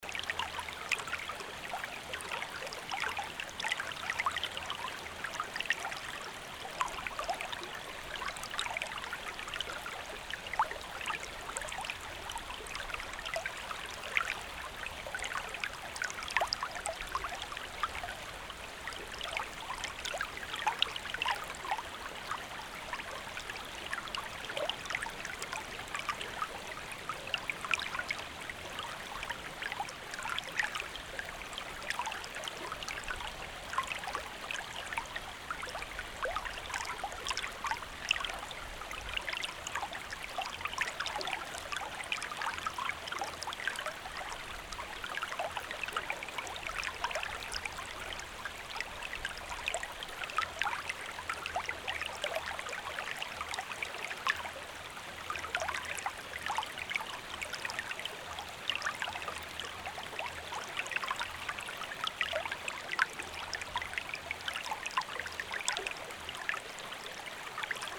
Sound Effects Library. Forest Stream (CC BY)
Facede a proba mentres escoitades este fondo de ambientación sonora tan zen!
sound-effects-library-forest-stream.mp3